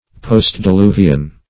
Postdiluvian \Post`di*lu"vi*an\, n.